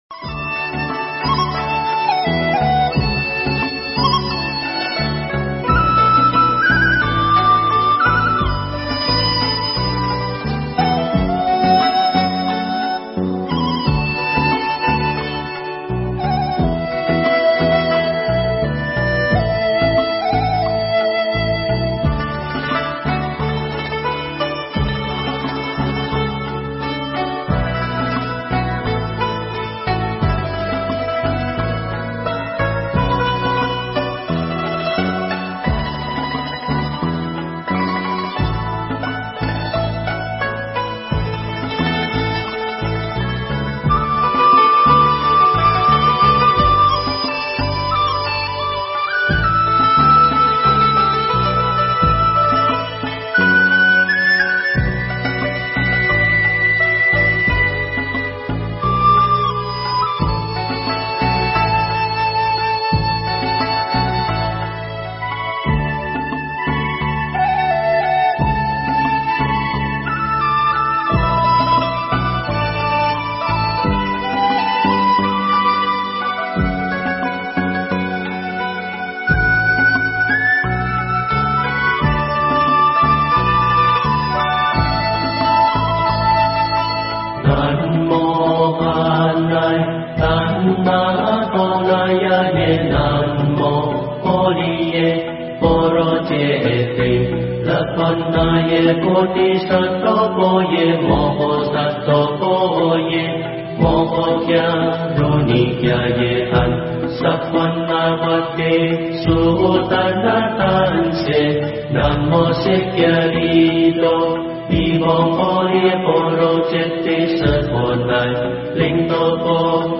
Nghe Mp3 thuyết pháp Ý Nghĩa Cầu Đạo Theo Chánh Pháp
Nghe mp3 pháp thoại Ý Nghĩa Cầu Đạo Theo Chánh Pháp